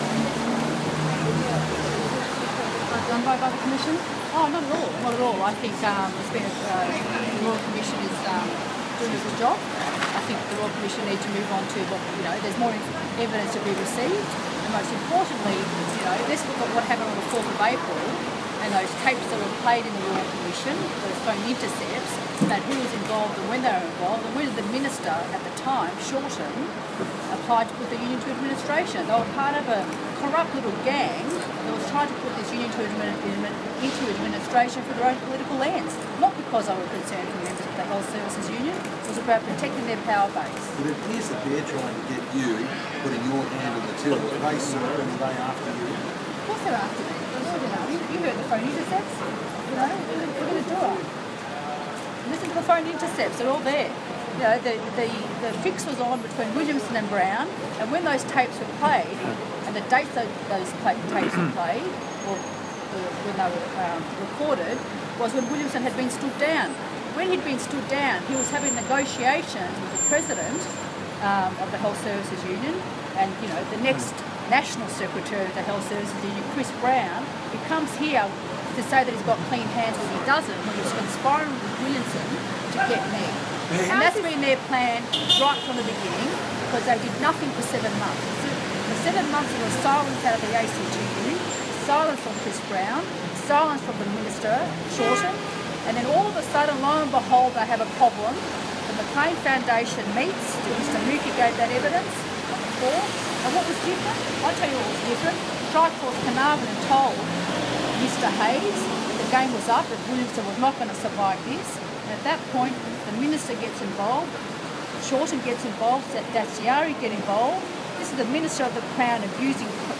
Virtual Insanity - Kathy Jacksons bizarre press conference - Wixxyleaks
Spitting venom outside the Royal Commission
At one stage in the interview a reporter asks;
At the end of the recording that is below, you will hear Jackson being asked if she has ever used a union slush fund for personal purposes.
Jackson-Doorstop.m4a